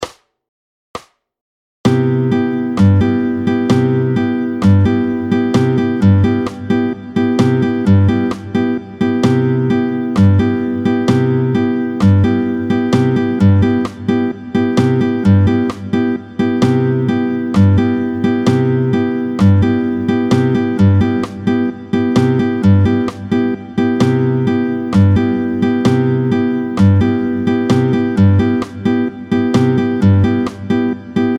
30-03 Motif de base en C7M. Vite, 2/2 tempo 130